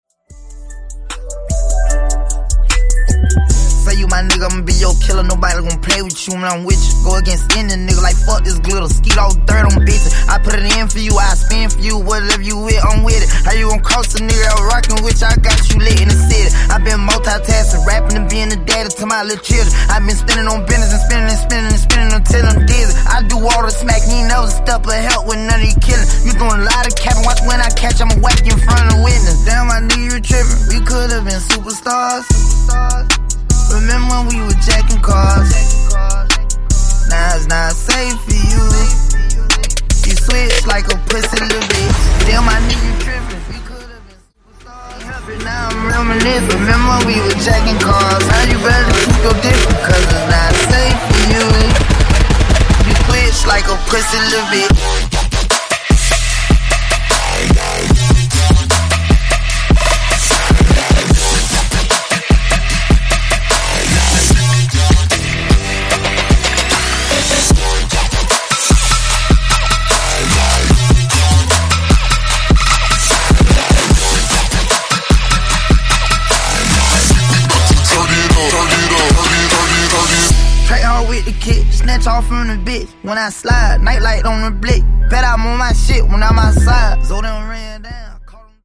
BPM: 75 Time